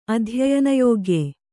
♪ adhyayanayōgye